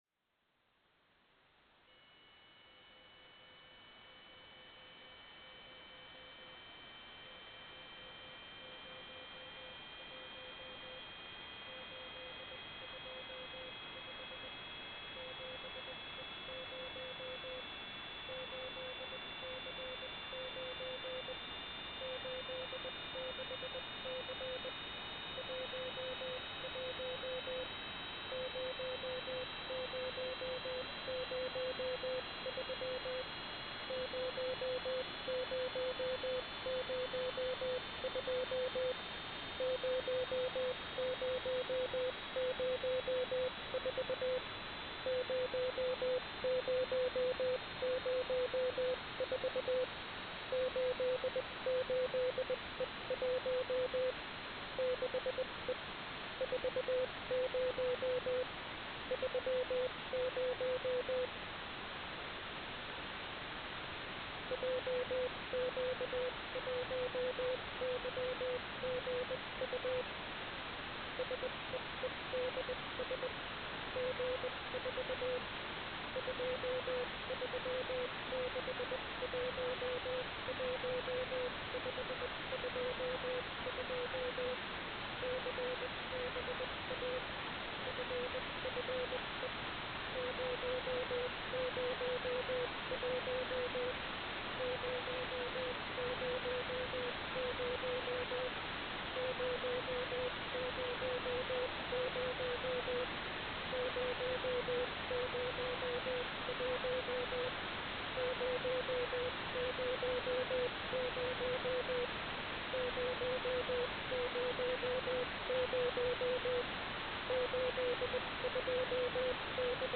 "transmitter_mode": "CW",